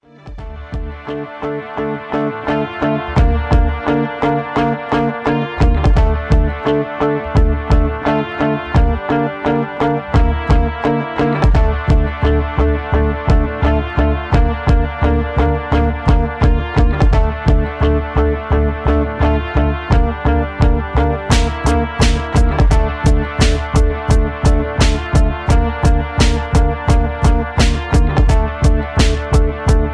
karaoke, hip-hop, rap